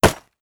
Knife_Wood.wav